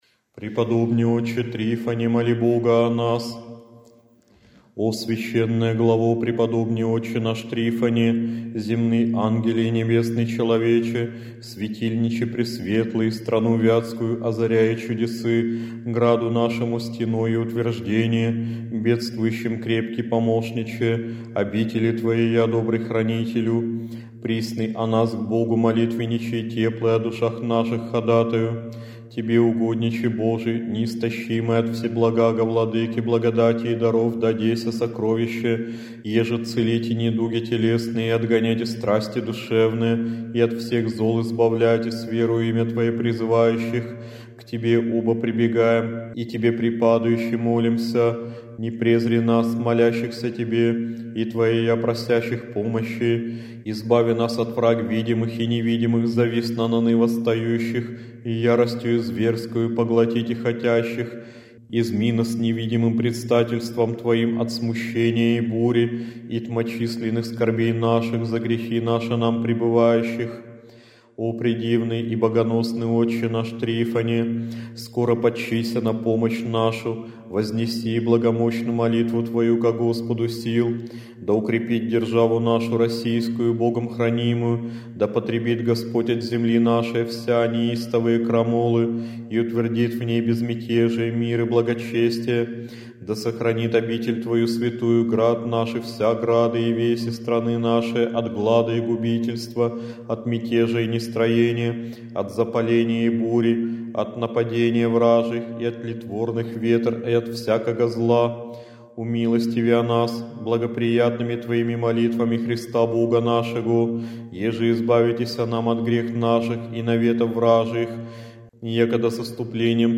Молитва
prep_trifonu_vjatskomu_molitva.mp3